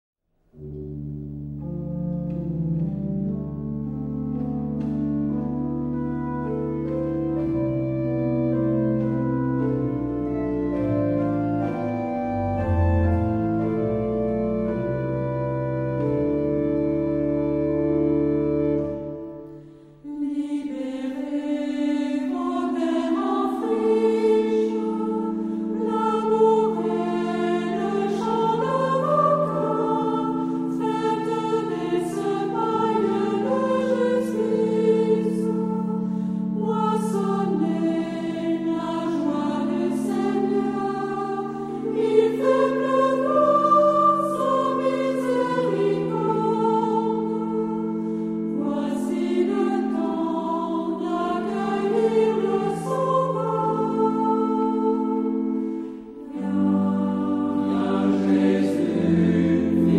Genre-Style-Form: troparium ; Sacred
Mood of the piece: collected
Type of Choir: SATB  (4 mixed voices )
Instruments: Organ (1)
Tonality: dorian